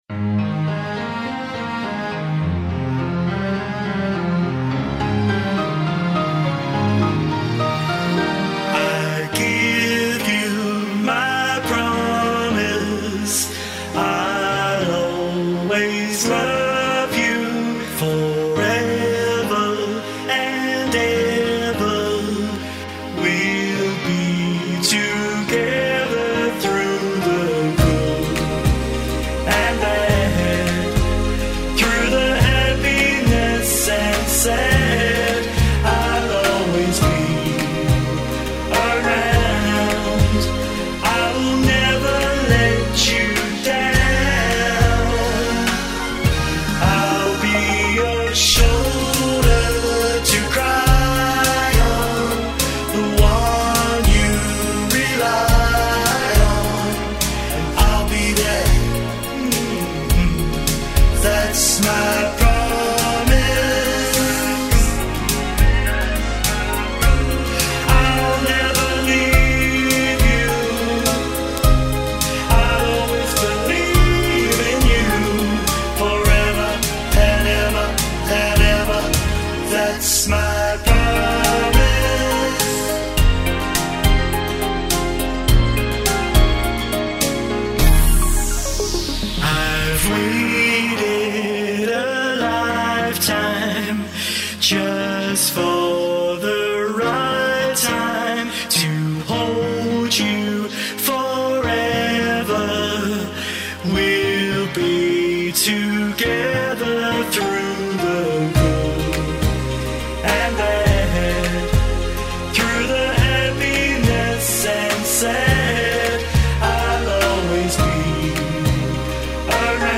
the wedding song you’ve been waiting for.
heartfelt ballad